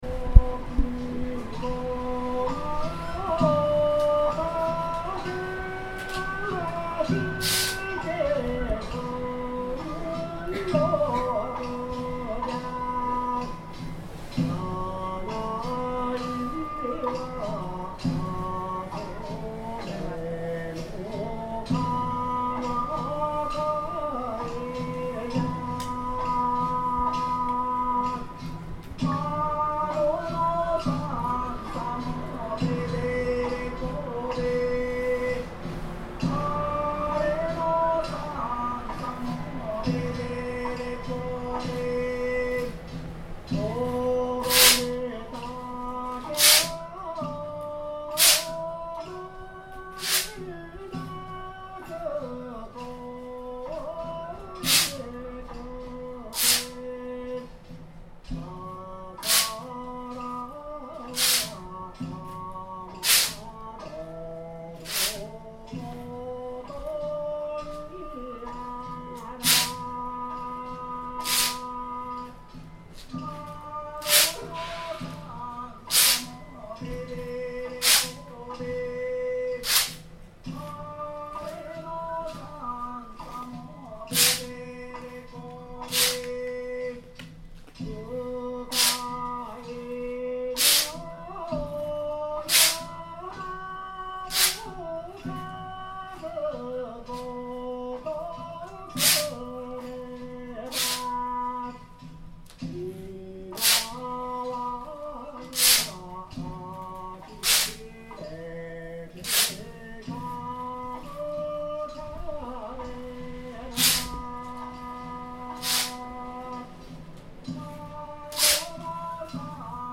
traditional dance. A man, in traditional clothes, plays a song on a little stereo and dances in front of a small audience. The setting is a traditional house made of dark timber, embers shine in the ash pit in the middle of the room. As he dances he plays the binzasara, a traditional instrument that can double as a decoration to ward off evil at home.